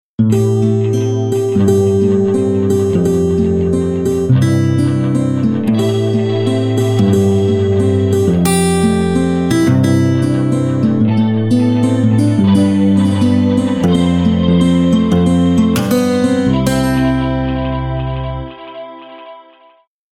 Style: Semi-Acoustic